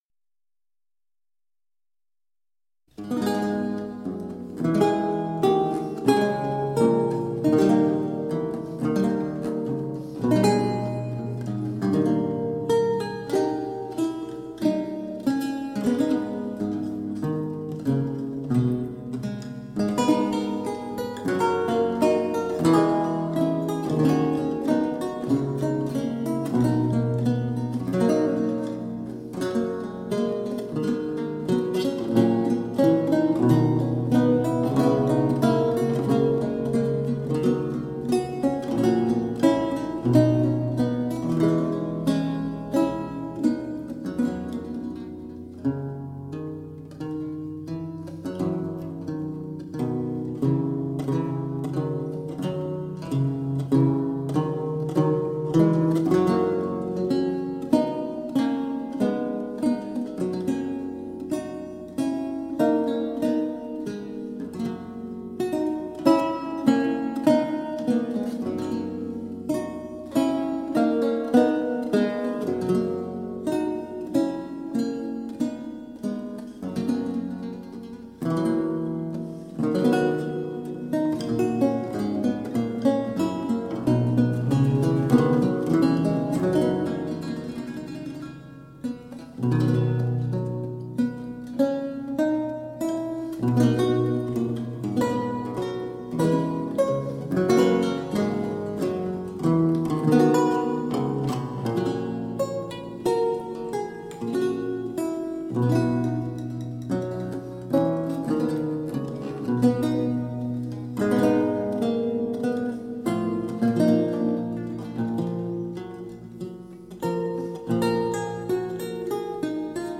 Vihuela, renaissance and baroque lute.